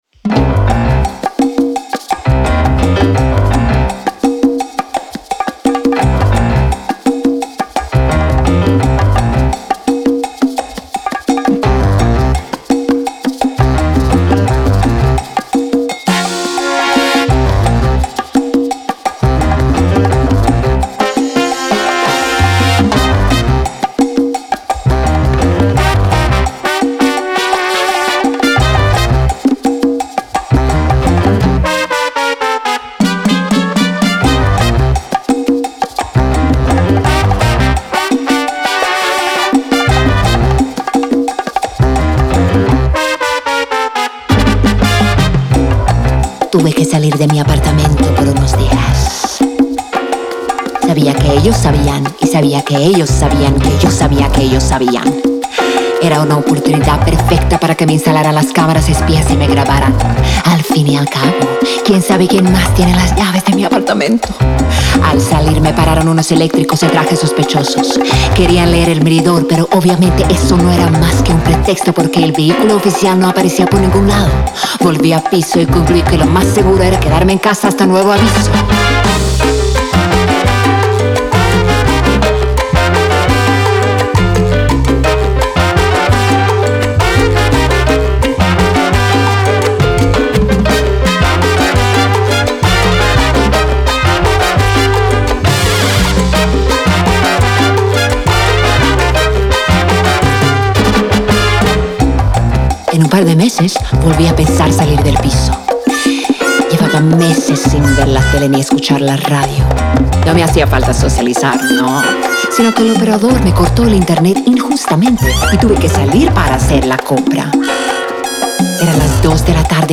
fusionar sonidos tradicionales croatas con ritmos latinos